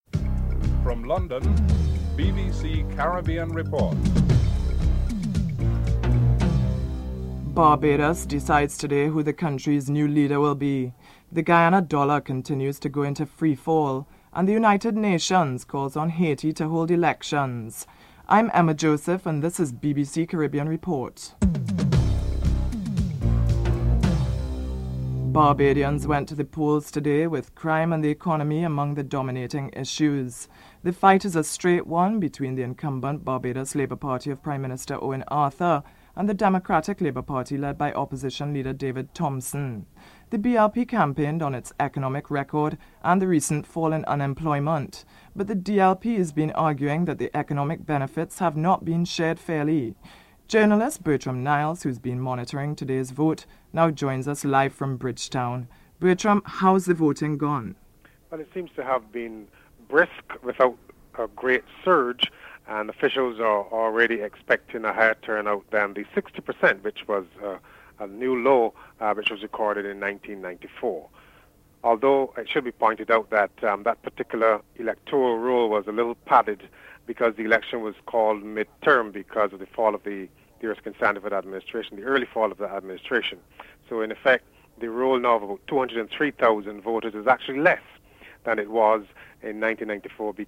1. Headlines with anchor